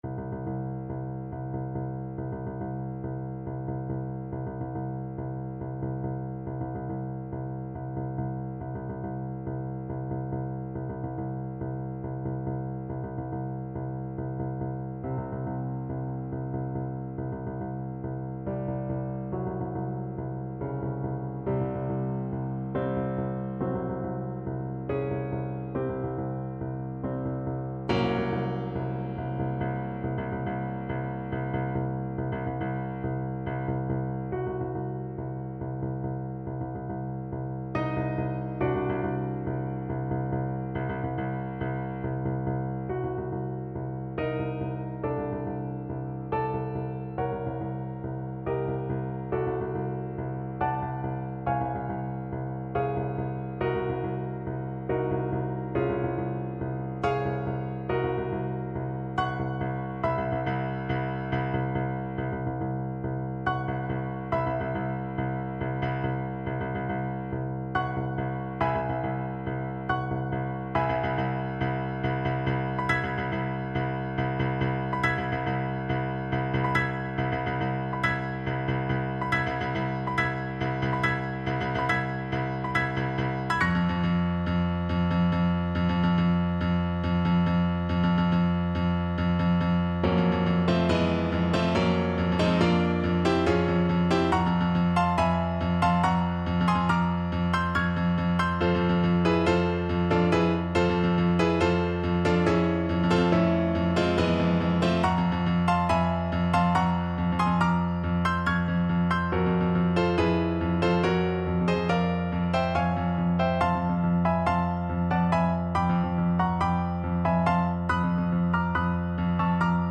Play (or use space bar on your keyboard) Pause Music Playalong - Piano Accompaniment Playalong Band Accompaniment not yet available reset tempo print settings full screen
5/4 (View more 5/4 Music)
D minor (Sounding Pitch) A minor (French Horn in F) (View more D minor Music for French Horn )
Allegro = 140 (View more music marked Allegro)
Classical (View more Classical French Horn Music)